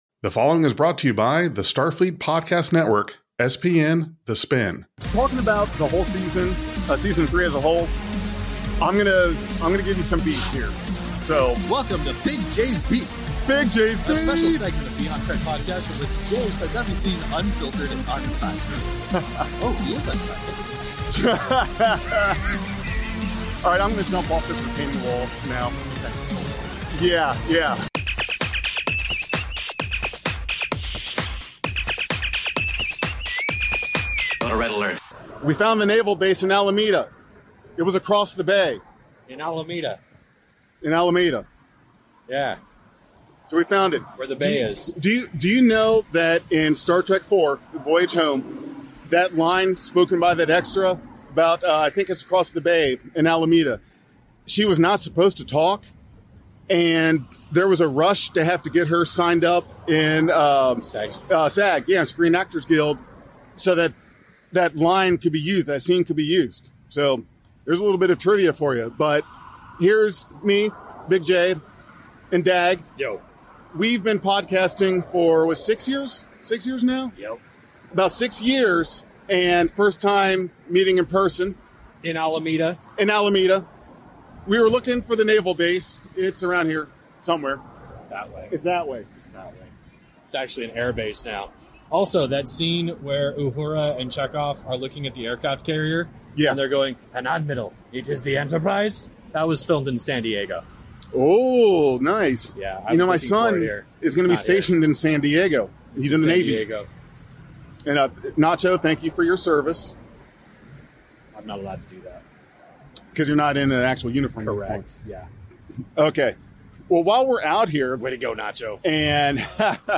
Three people from across the U.S. join forces every week to share how Star Trek is impacting lives here and abroad every single day.
The boys meet up in Alameda (where they keep the nuclear wessels) to talk about Star Trek: Strange New Worlds Season 3